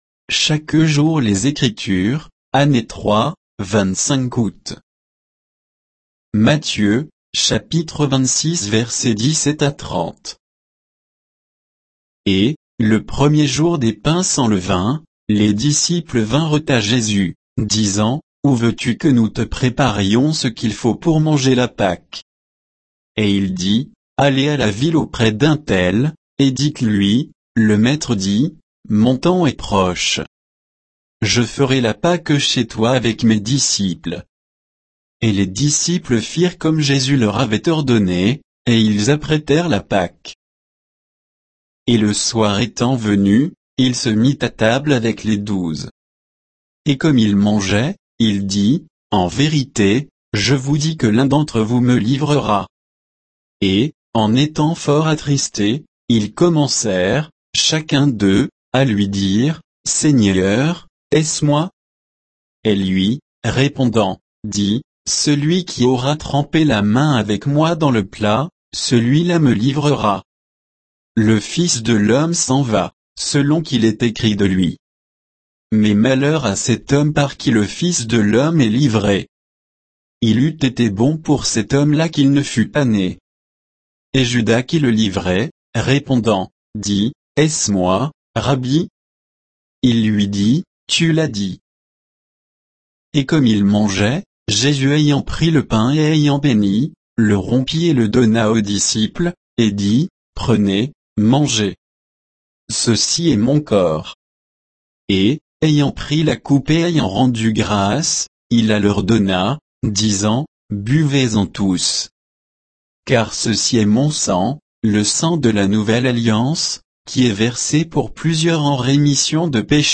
Méditation quoditienne de Chaque jour les Écritures sur Matthieu 26